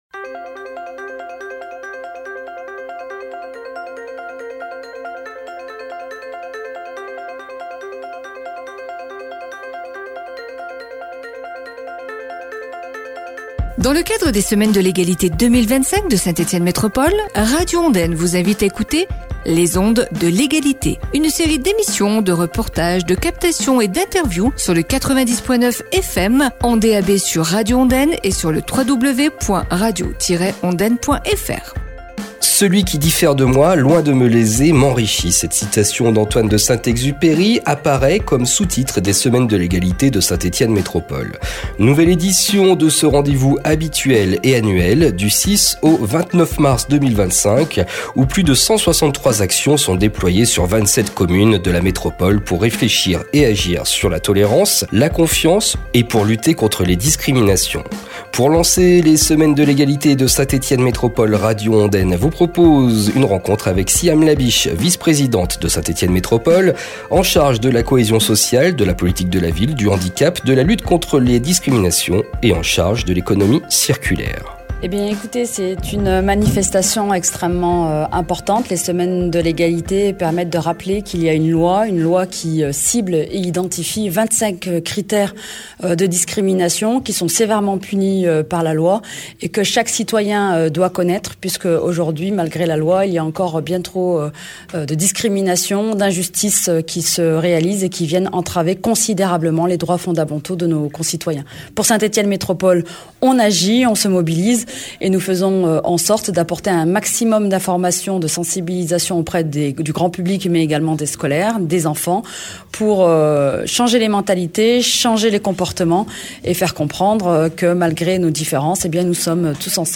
Pour cette première émission des Ondes de l’Égalité, nous vous proposons une rencontre avec Siham Labich, vice-présidente de Saint-Etienne Métropole, en charge de la cohésion sociale, de la politique de la ville, du handicap et de la lutte contre les discriminations. Présentation de ce rendez-vous habituel et annuel sur la métropole, qui voit 163 actions se déployer sur 27 communes, du 06 au 29 Mars, pour réfléchir, agir sur la tolérance, la confiance, pour lutter contre les discriminations et favoriser le vivre ensemble.